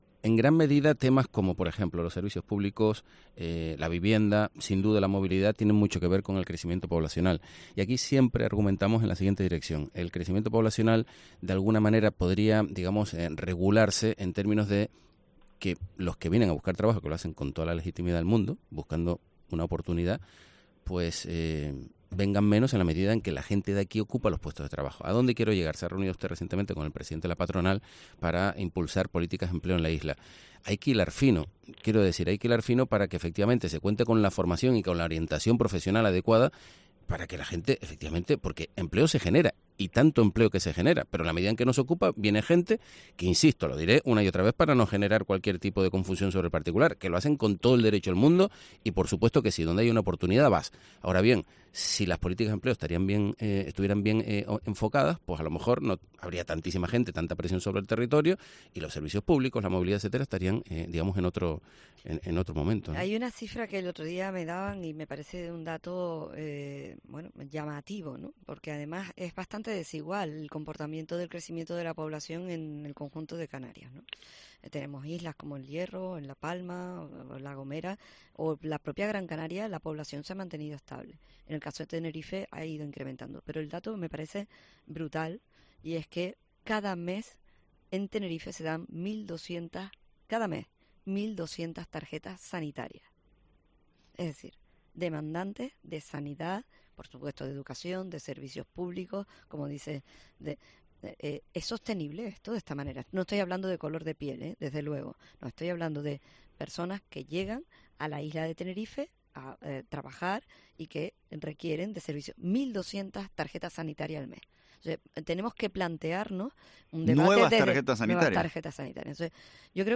La presidenta del Cabildo de Tenerife, Rosa Dávila, ha pedido en los micrófonos de Herrera en COPE Canarias "un debate sosegado y tranquilo sobre lo que es exactamente la insularidad y la capacidad de carga del territorio".